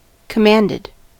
commanded: Wikimedia Commons US English Pronunciations
En-us-commanded.WAV